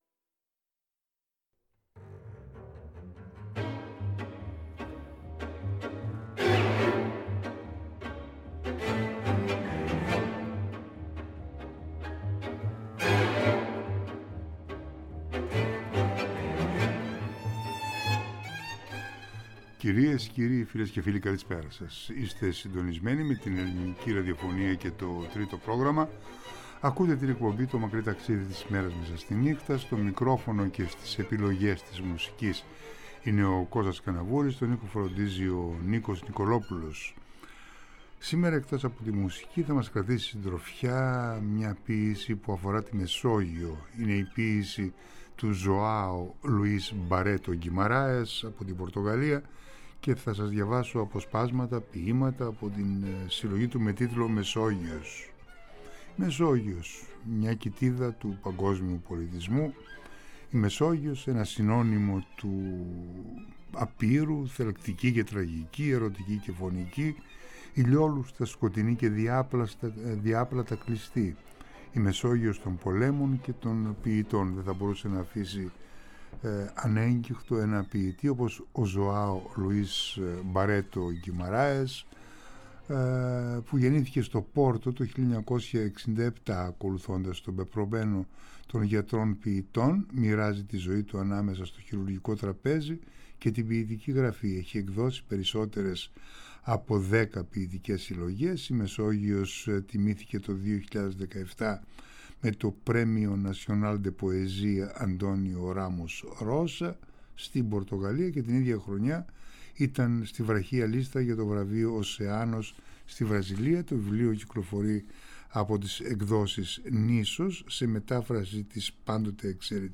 διαβάζει κάποια ποιήματα , στην αποψινή εκπομπή σε απ’ ευθείας μετάδοση, στο Τρίτο Πρόγραμμα.